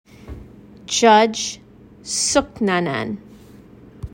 For the pronunciation of Judge Sooknanan's name, please click